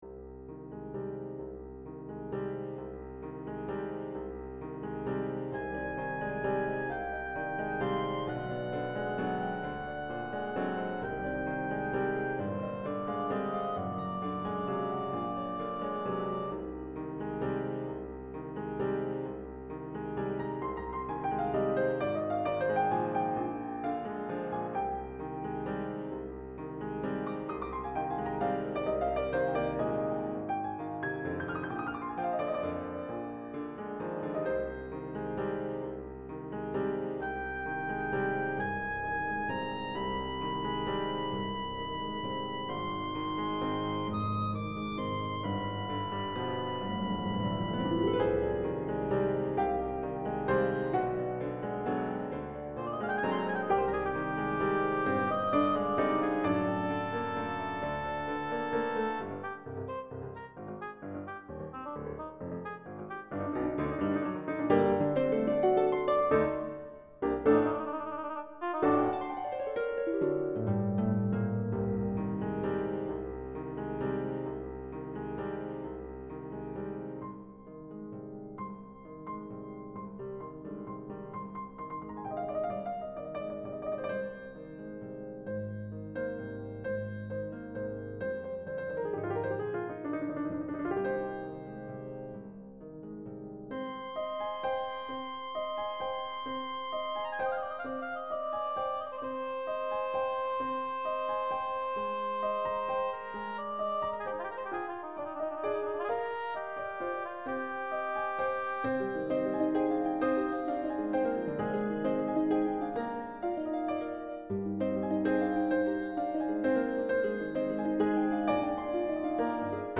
Prelude to Thought (piano, harp, and oboe) – 2007